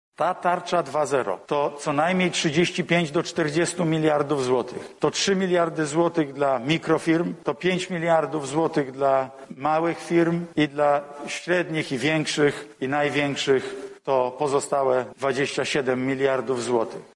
• tłumaczył premier podczas dzisiejszej konferencji.